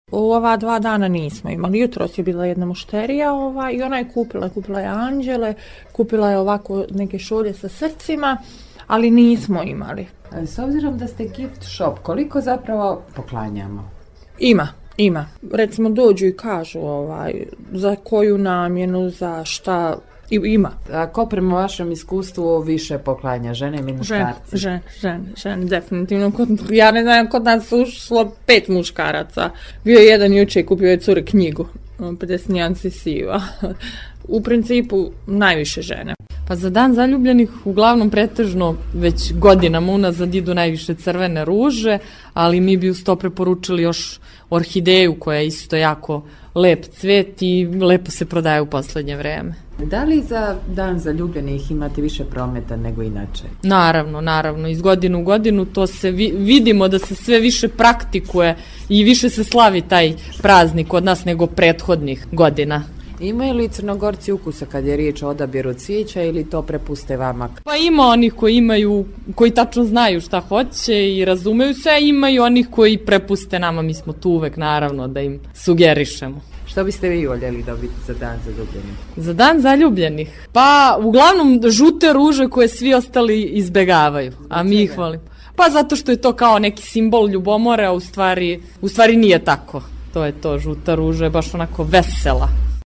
Ples protiv nasilja održan je na Dan zaljubljenih i to je bila prilika da pitamo Budvanke i Budvane koliko kupuju poklone na Svetog Valentina.